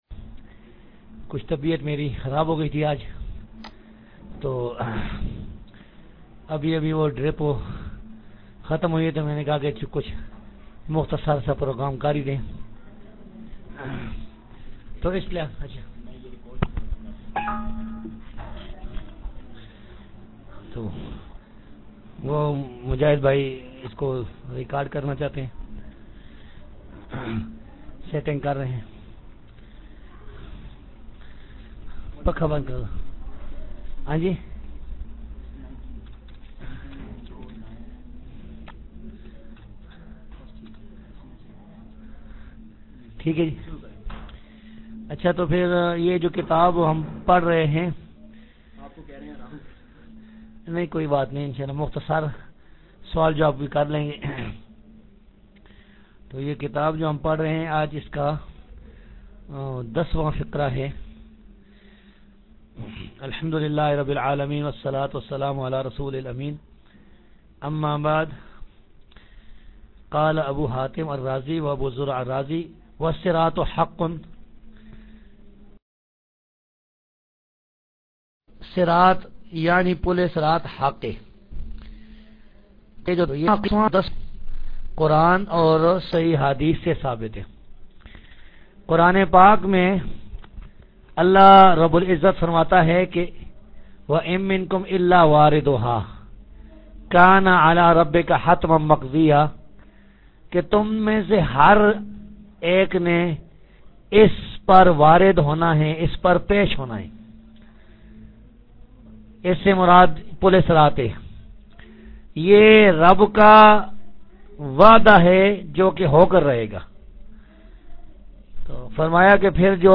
Dars-e-Hadeeth Aur Sawaal Jawaab 19-9-04